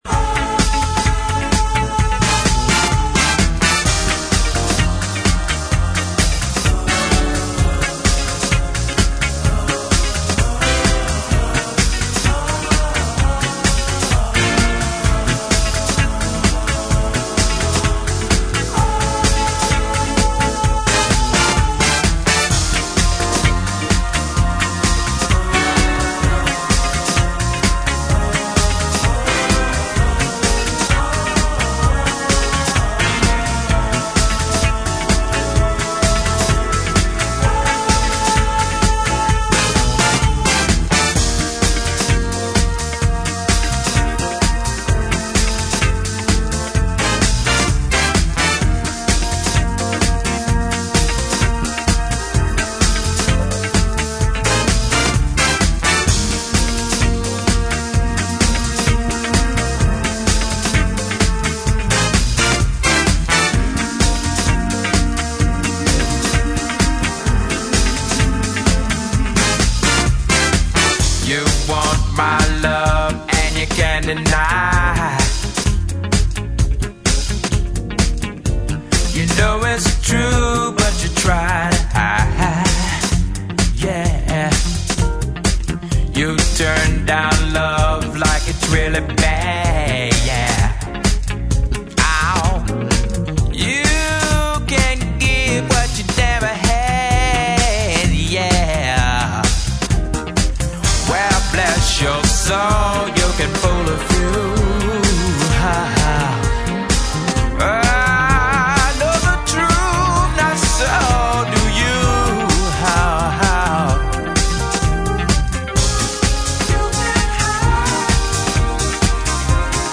ジャンル(スタイル) DISCO / SOUL